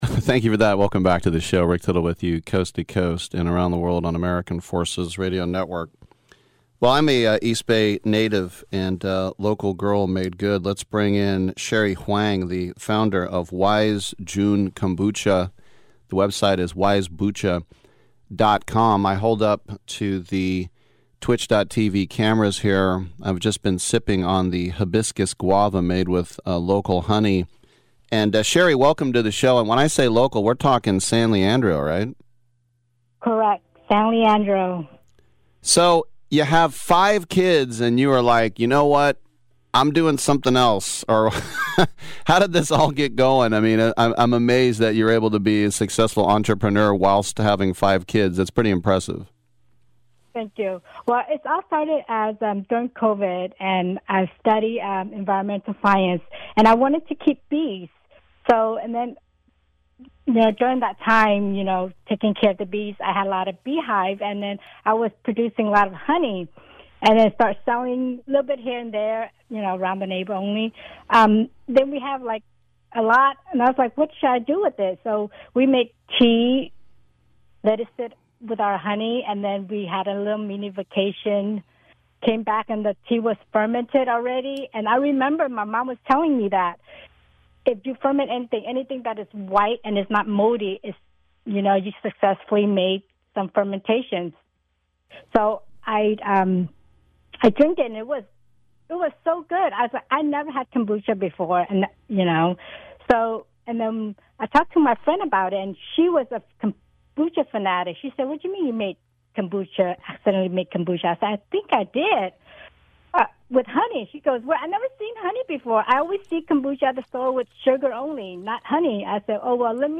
Live Interview with Sports Byline USA
Here’s a live interview clip from Sports Byline USA!